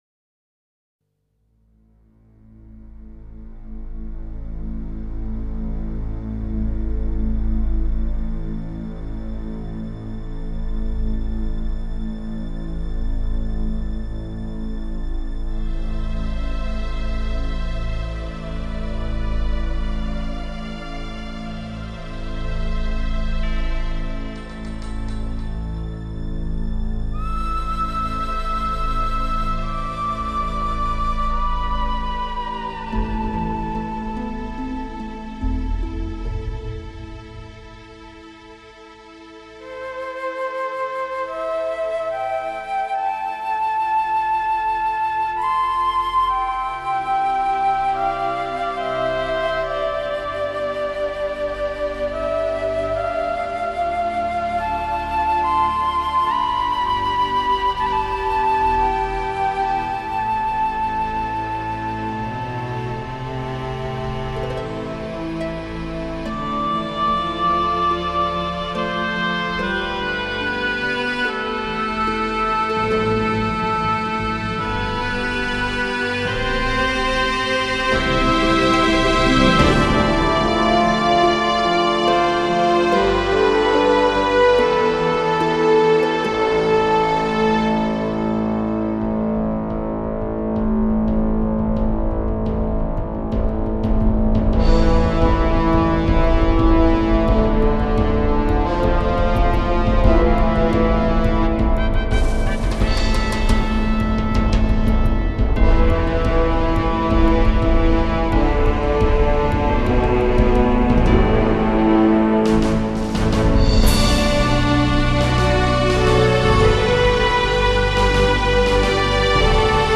Sympho Metal